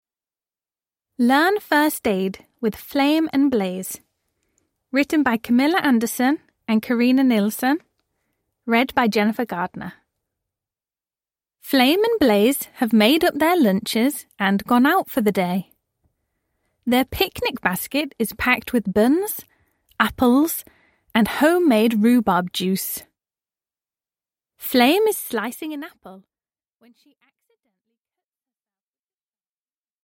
Flame and Blaze - Learn first aid – Ljudbok